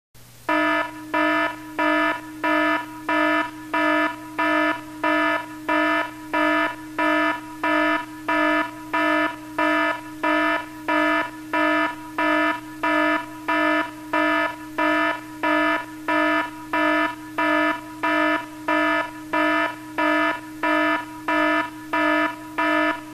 Alarm 02